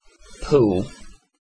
Ääntäminen
Ääntäminen UK Tuntematon aksentti: IPA : /puːl/ Haettu sana löytyi näillä lähdekielillä: englanti Käännöksiä ei löytynyt valitulle kohdekielelle.